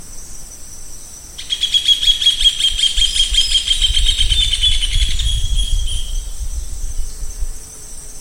Hornero (Furnarius rufus)
Nombre en inglés: Rufous Hornero
Fase de la vida: Adulto
Localidad o área protegida: Reserva Natural del Pilar
Condición: Silvestre
Certeza: Vocalización Grabada
Hornero.mp3